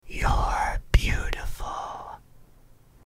(Guy Whispering) - You're Beautiful
Category: ASMR Mood: Relax Editor's Choice